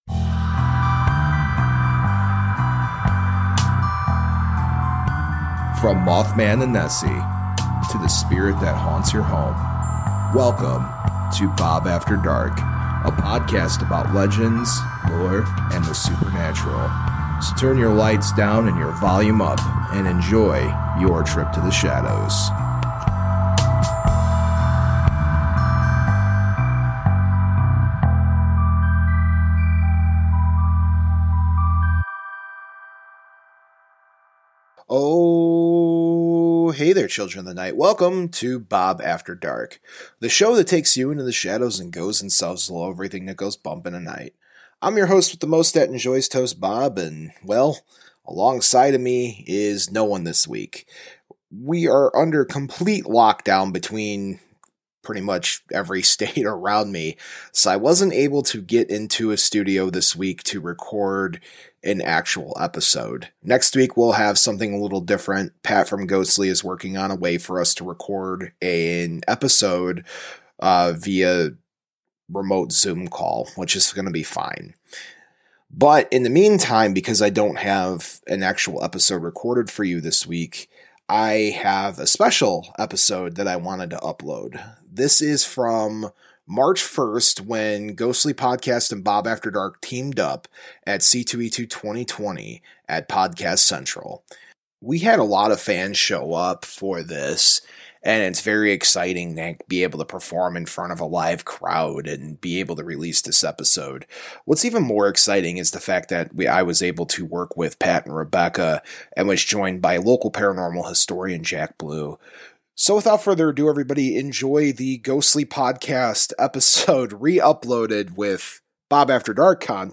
This was recorded live at Podcast Central at C2E2 this year, and needless to say, it was a huge success.